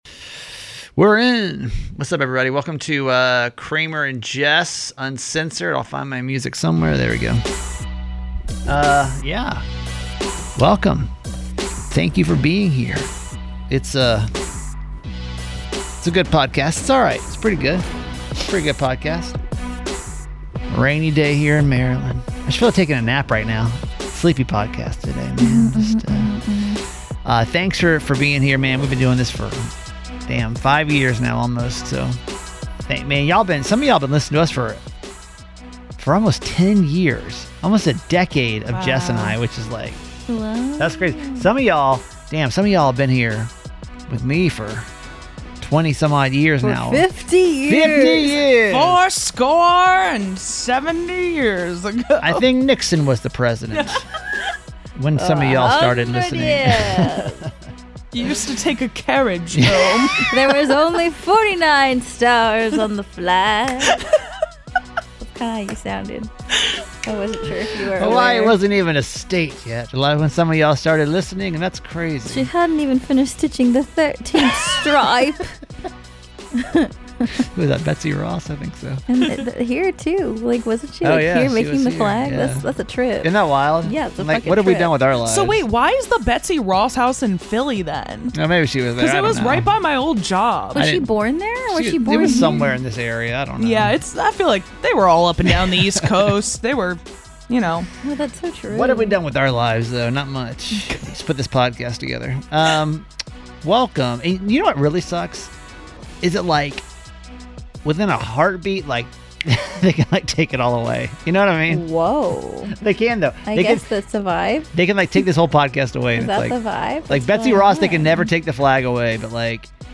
Hear unedited thoughts, phones calls, and hot takes that you won’t get on the radio.